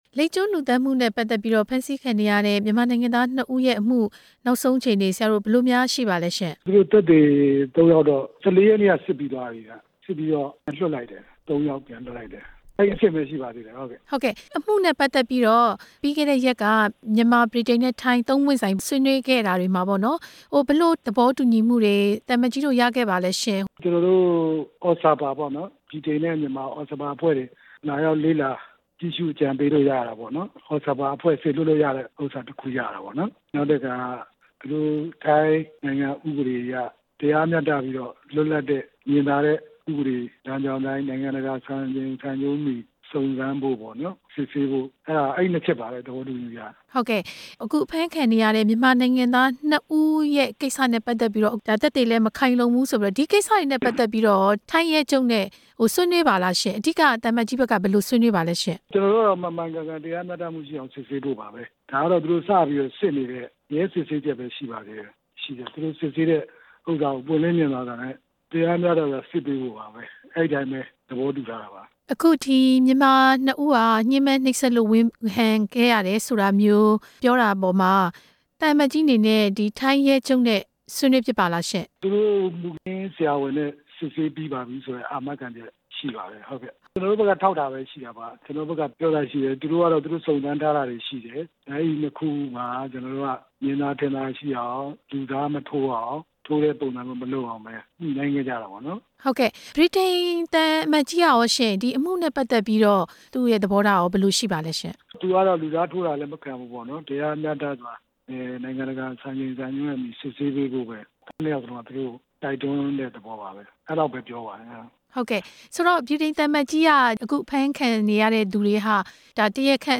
သံအမတ်ကြီး ဦးဝင်းမောင်ကို မေးမြန်းချက်